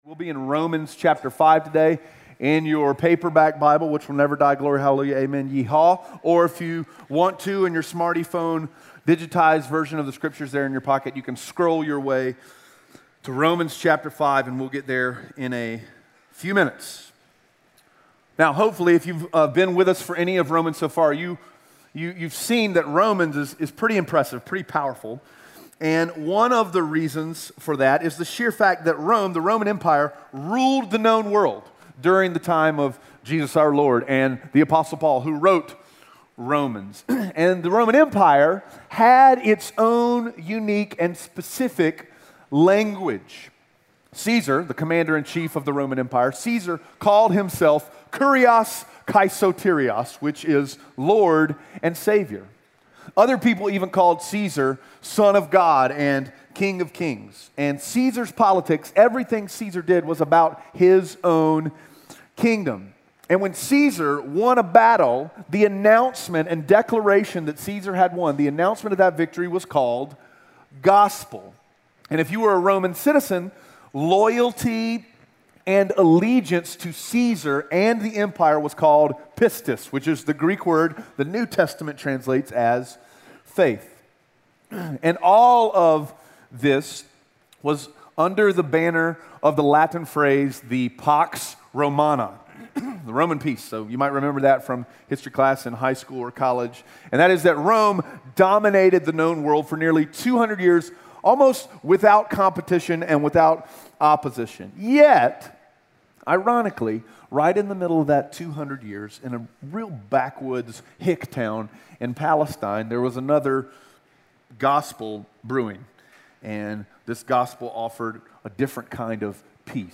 Romans 5:1-5 Audio Sermon Notes (PDF) Onscreen Notes Ask a Question *We are a church located in Greenville, South Carolina.